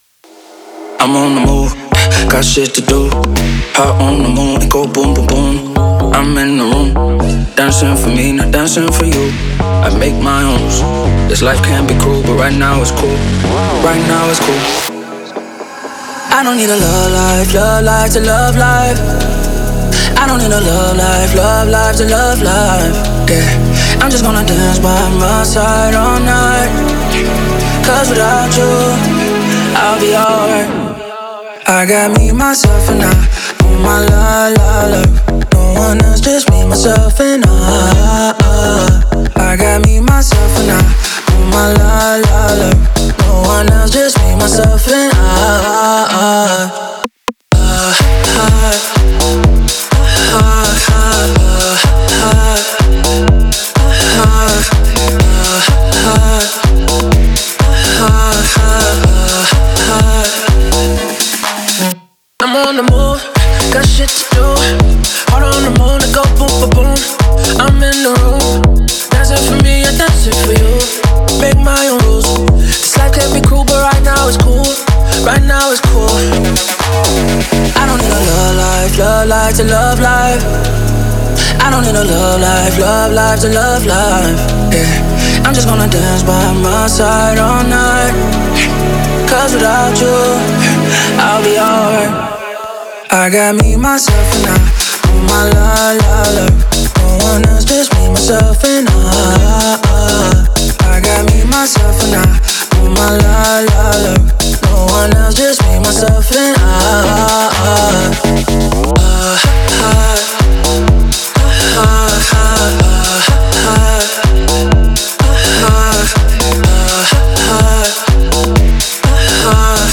это энергичный трек в жанре EDM
мелодичному вокалу